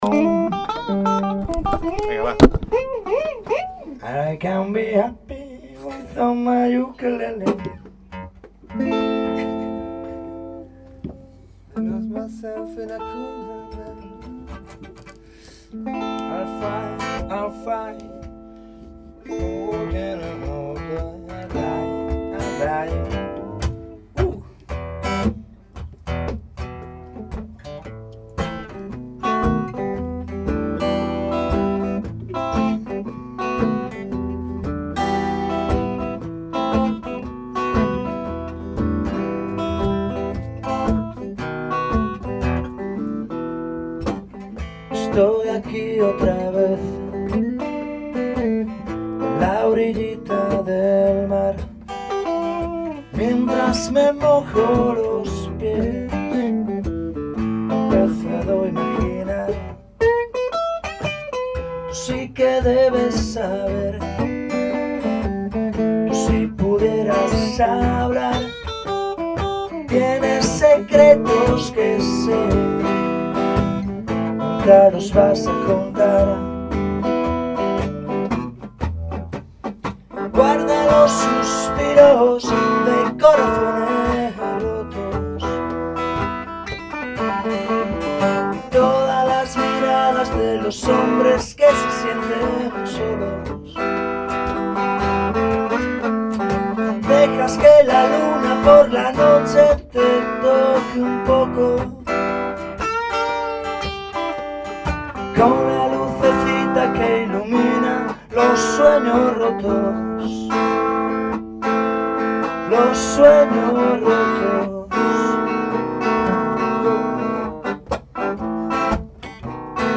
voz rasgada
guitarra solista y juguetona
guitarra acústica